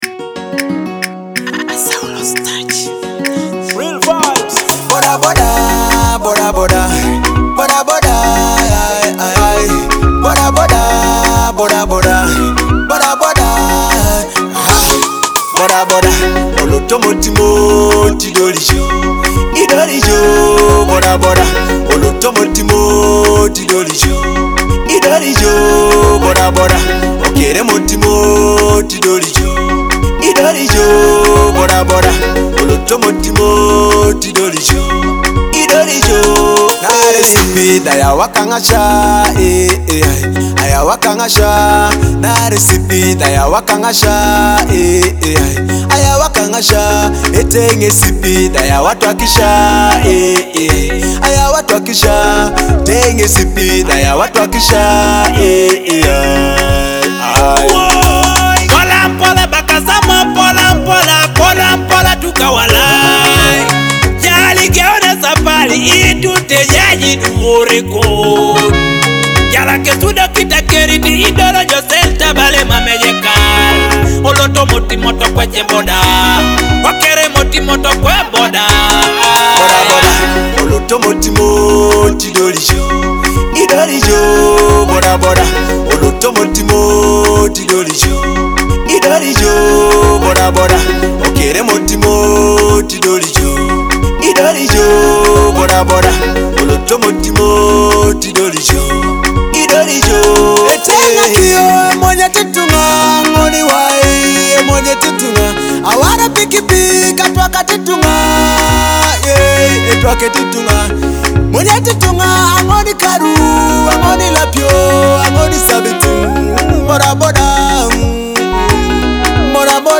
vibrant track